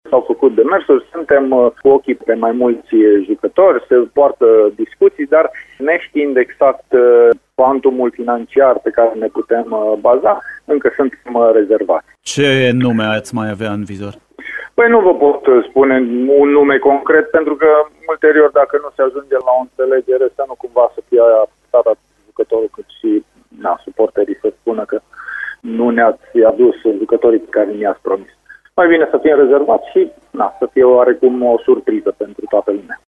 Prezent în direct la Radio Timișoara